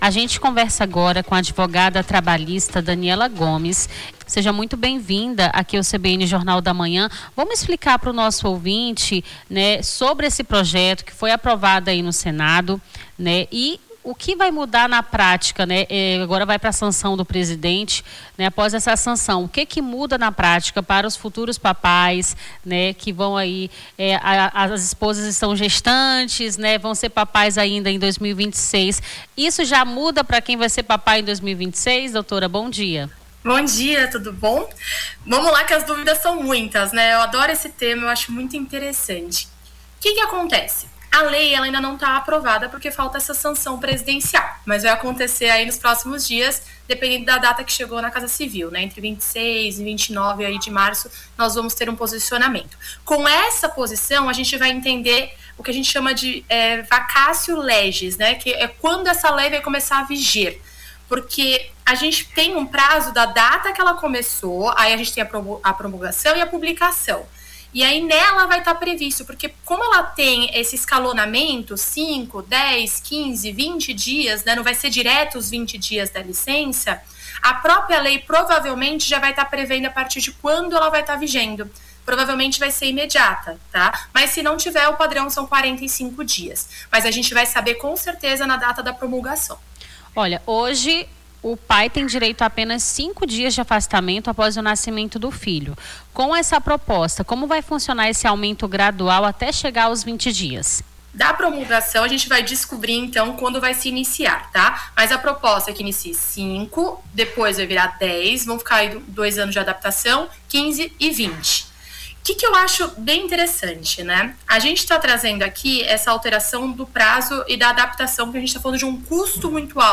ENTREVISTA LICENÇA PATERNIDADE